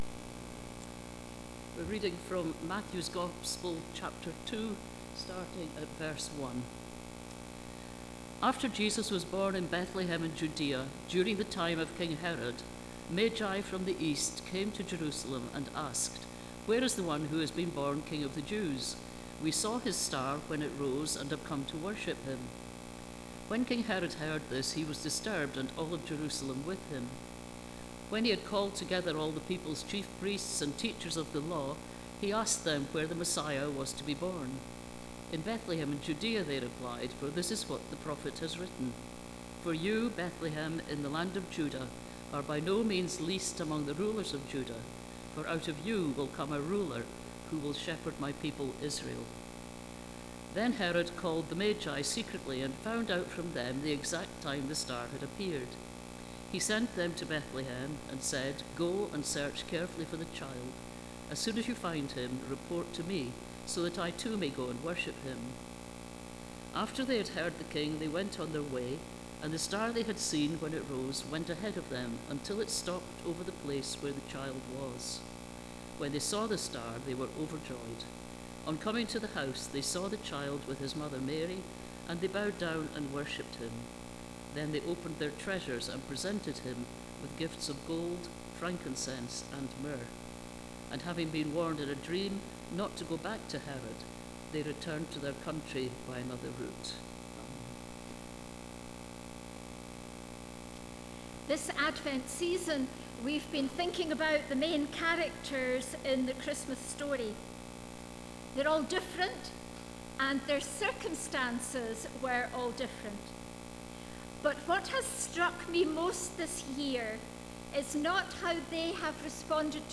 Advent Series Passage: Matthew 2: 1-12 Service Type: Sunday Morning « Mary